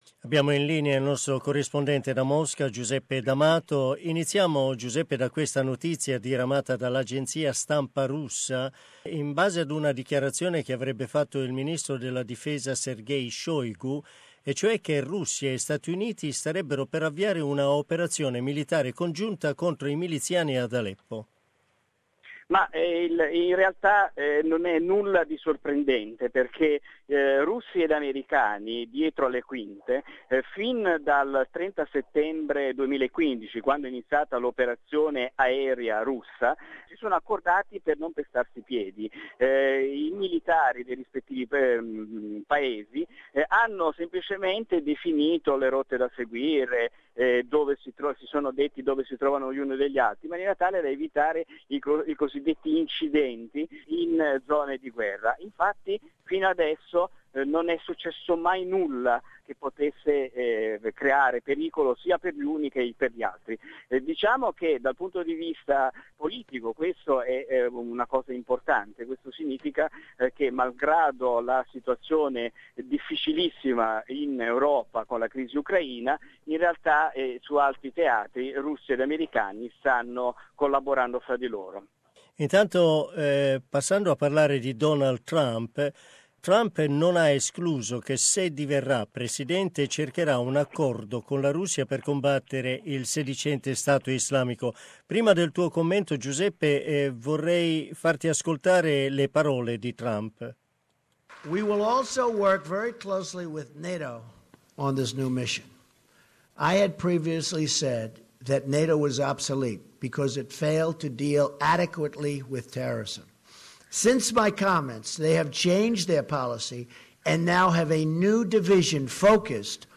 Russian Defence Minister announces joint military action with USA in Aleppo. We hear from our correspondent in Moscow